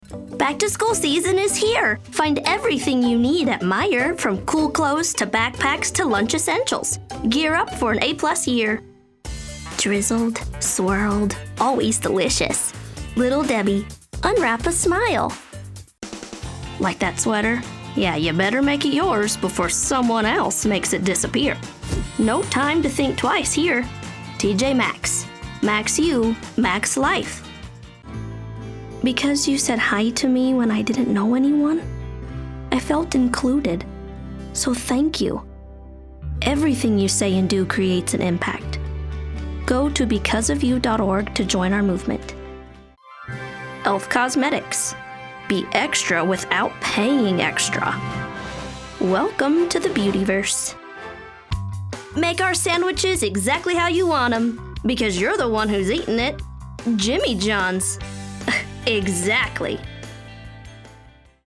Best Female Voice Over Actors In December 2025
Professionnal, soft, smile, friendly according to the project.